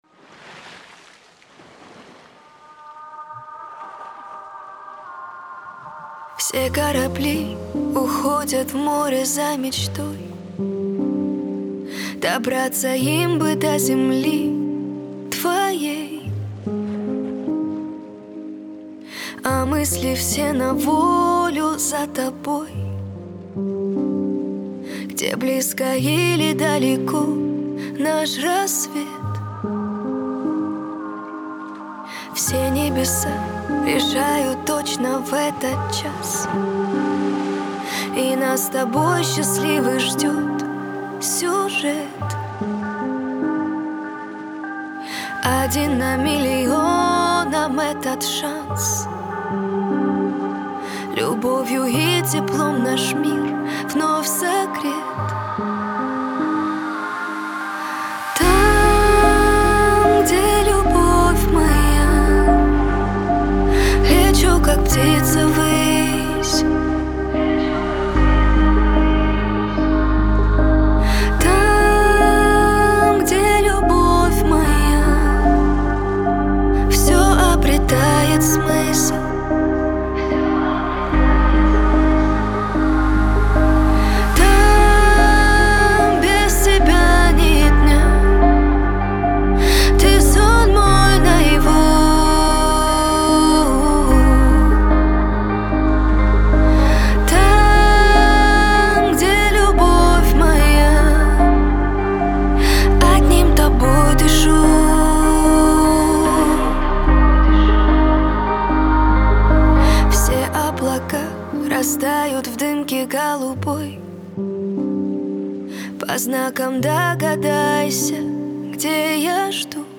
проникновенная песня
поп, наполненная эмоциями и меланхолией.